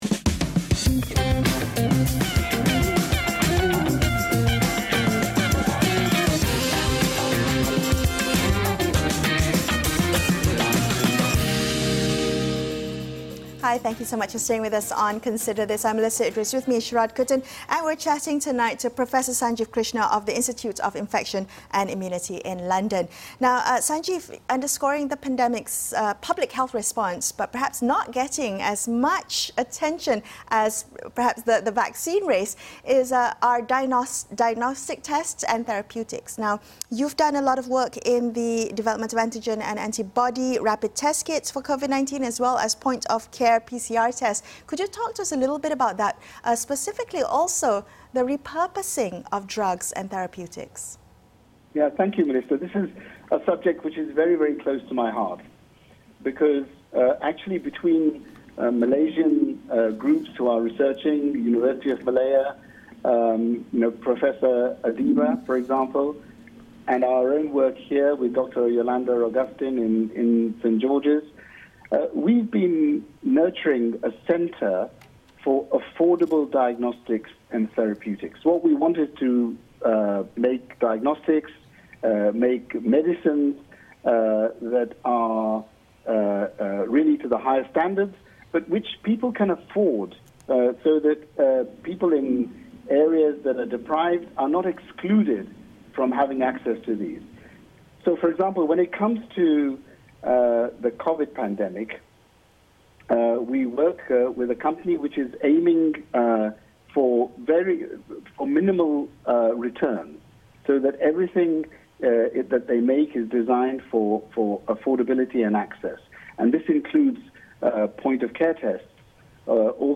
Our guest explains why the development of affordable diagnostics and therapeutics, as part of the public health tool kit, is so important.